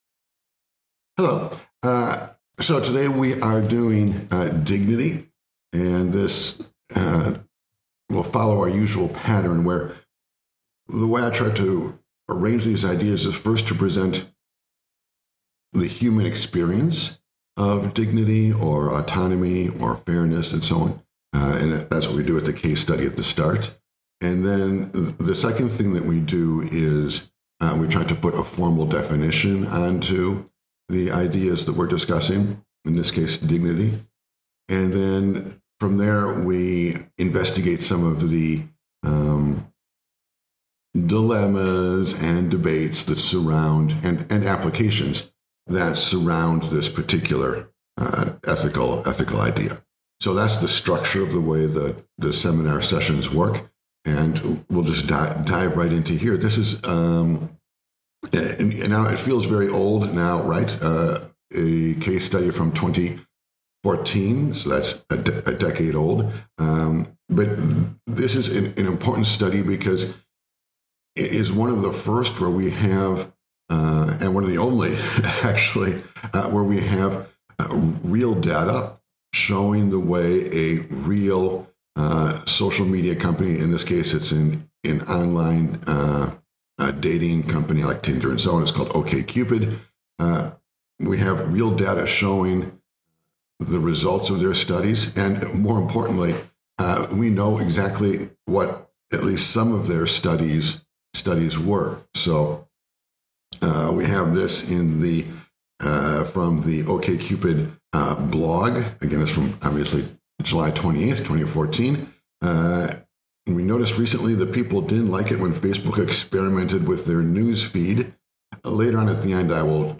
Lecture In this lecture we explore the AI ethics of Dignity Short on time?